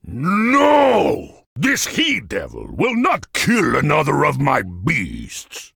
beyond/Assets/Sounds/Enemys/Giant/G_noo_heDevil.ogg at 37ba68bf8ffc8dfd6bf6dee0faf78136ee5ba22a